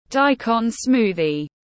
Sinh tố củ cải trắng tiếng anh gọi là daikon smoothie, phiên âm tiếng anh đọc là /ˈdaɪ.kɒn ˈsmuː.ði/
Daikon smoothie /ˈdaɪ.kɒn ˈsmuː.ði/